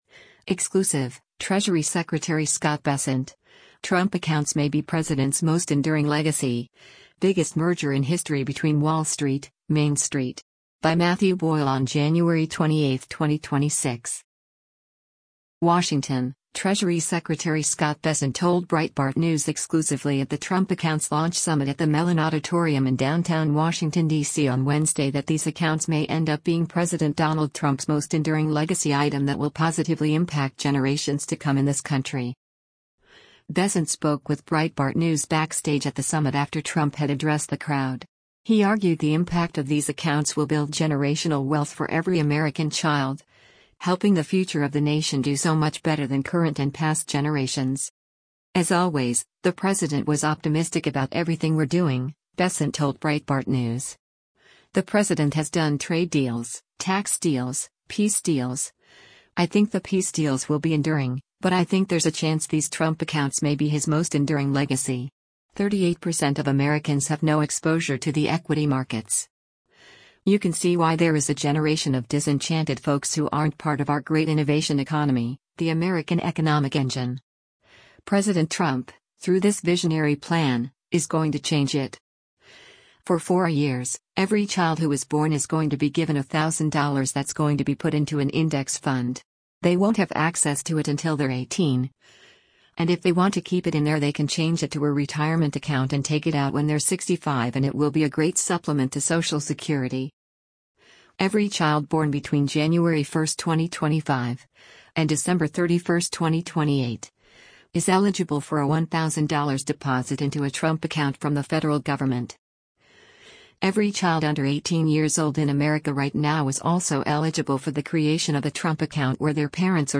WASHINGTON — Treasury Secretary Scott Bessent told Breitbart News exclusively at the Trump Accounts launch summit at the Mellon Auditorium in downtown Washington DC on Wednesday that these accounts may end up being President Donald Trump’s most “enduring” legacy item that will positively impact generations to come in this country.
Bessent spoke with Breitbart News backstage at the summit after Trump had addressed the crowd.